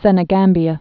(sĕnĭ-gămbē-ə)